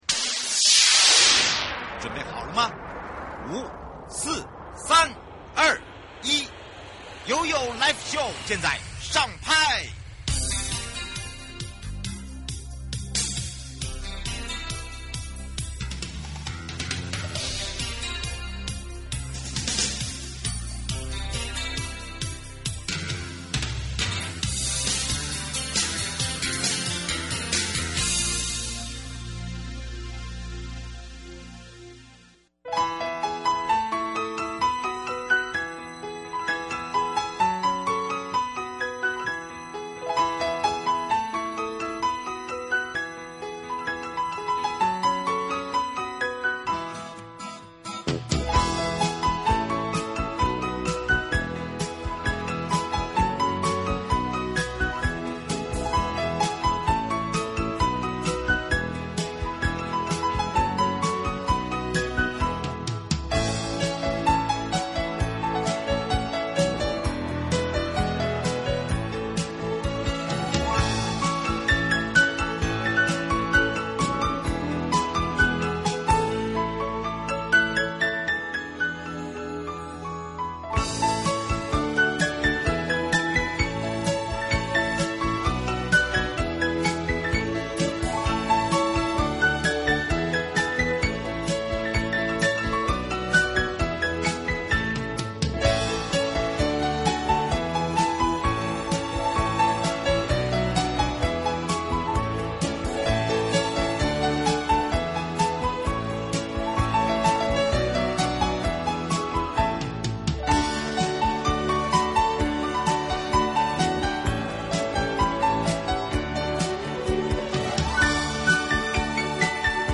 受訪者： 澎湖縣觀光旅遊處